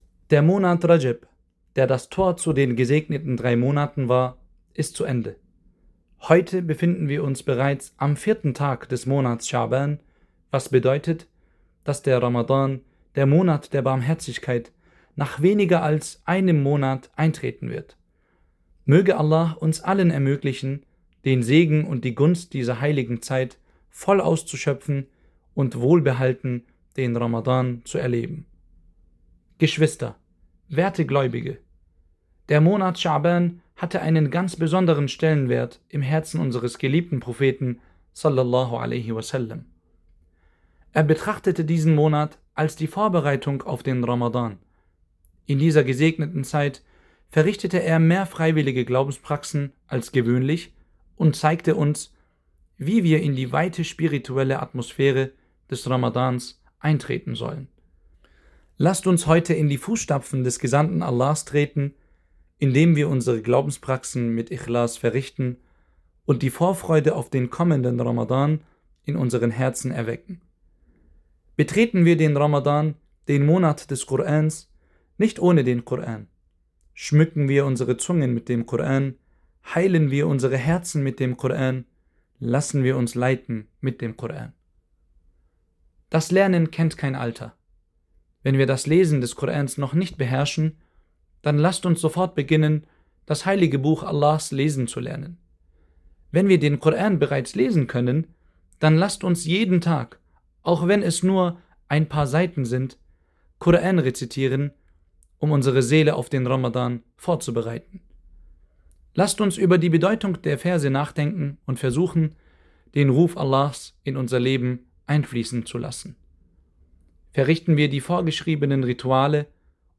Freitagspredigt